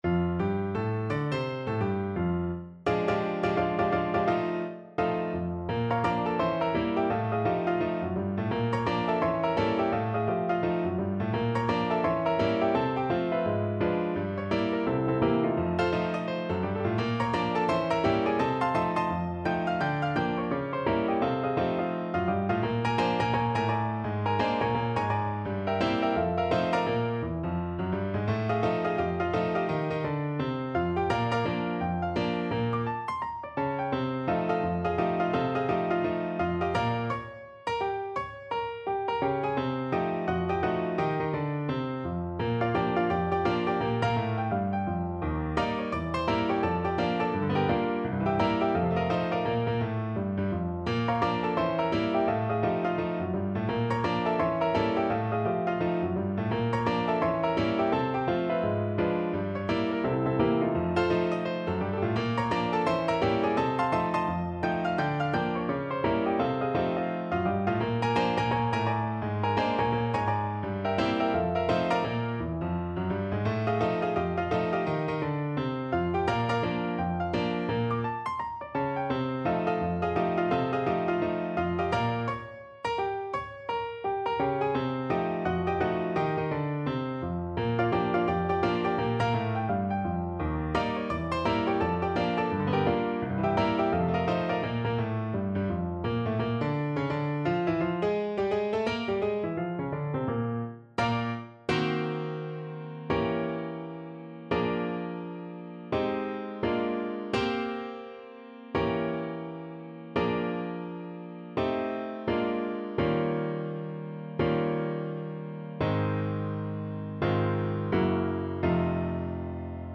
No parts available for this pieces as it is for solo piano.
2/2 (View more 2/2 Music)
Piano  (View more Advanced Piano Music)
Jazz (View more Jazz Piano Music)